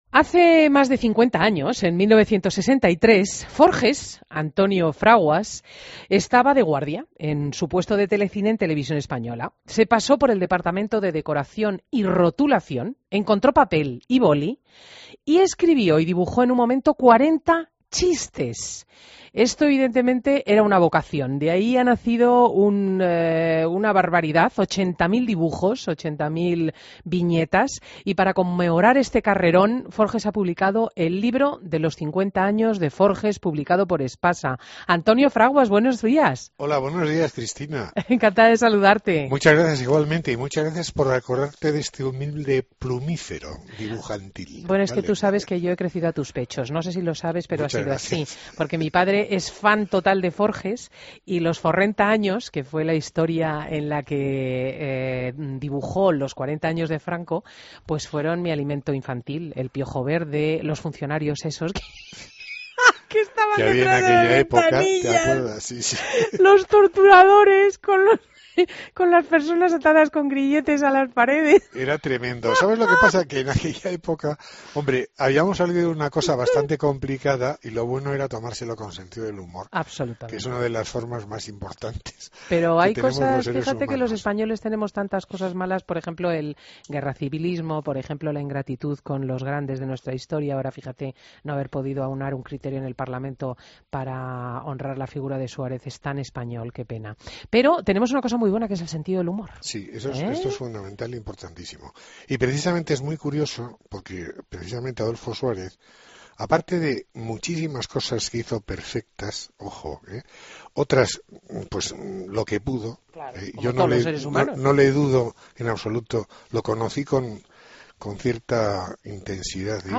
AUDIO: Entrevista a Antonio Fraguas "Forges" en Fin de Semana